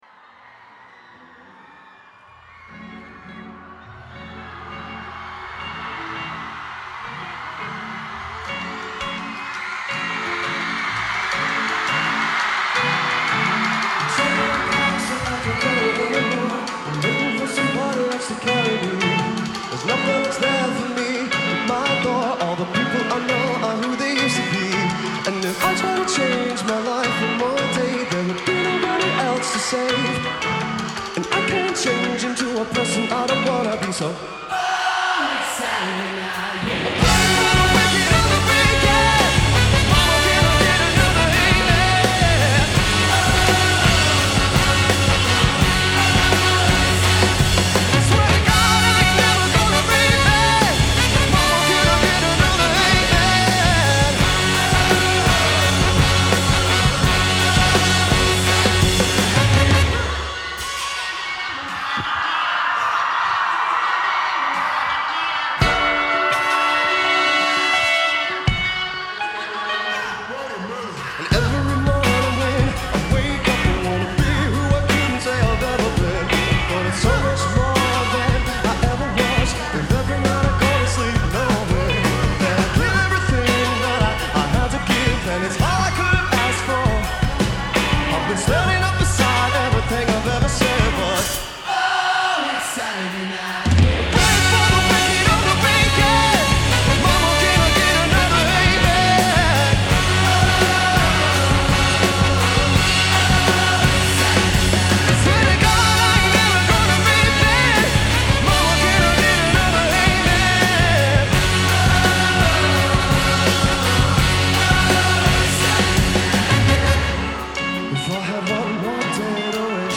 Biggest Weekend Festival.
during the BBC’s Biggest Weekend Festival in Swansea